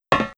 metalFootStep01.wav